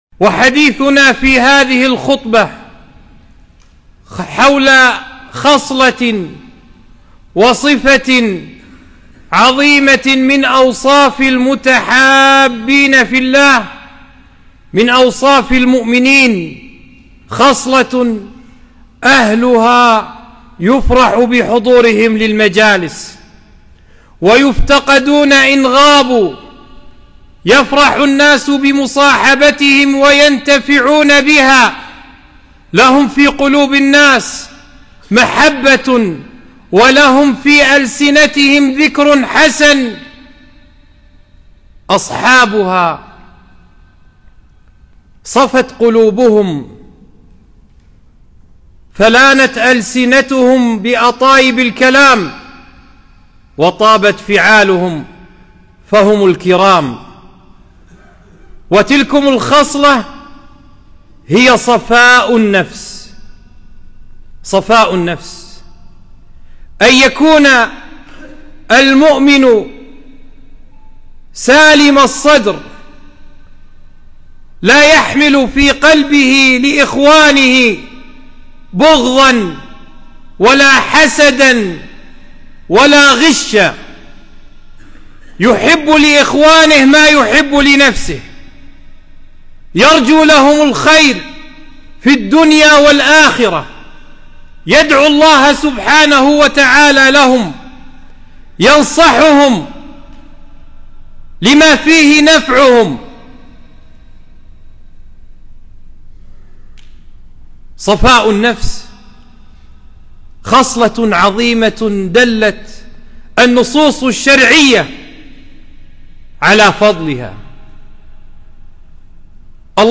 خطبة - سلامة الصدر